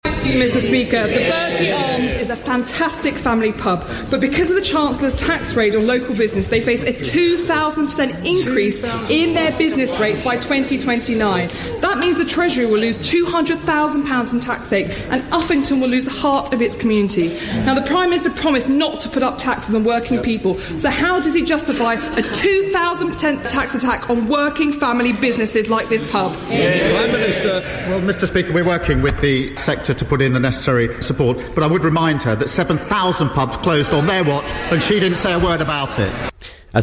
Rutland and Stamford MP Alicia Kearns raised it in the House yesterday and was disappointed with the Prime Minister's response as he pointed to pub closures under the Conservatives and didn't give many reassurances.